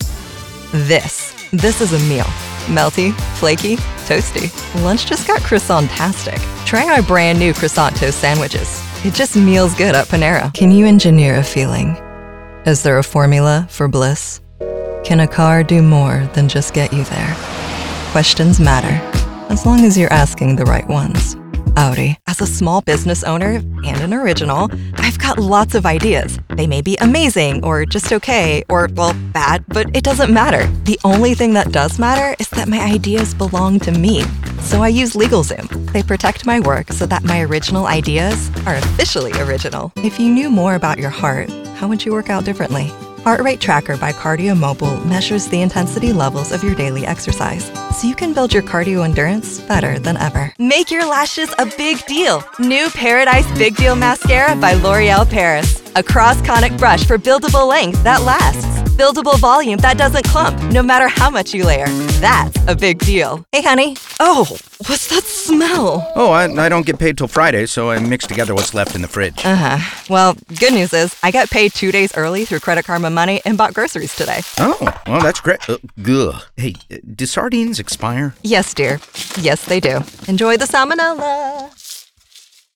Demos and Samples